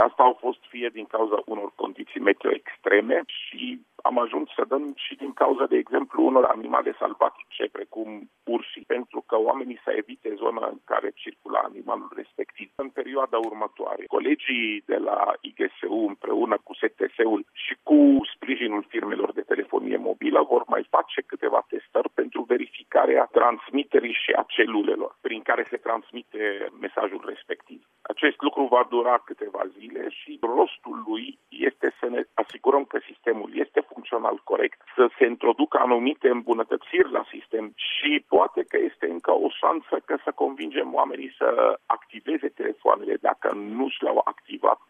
Invitat astăzi la Radio România Actualităţi, şeful Departamentului pentru Situaţii de Urgenţă, Raed Arafat, a precizat că, în cursul acestui an, au fost emise peste 400 de mesaje Ro-Alert, în toată ţara: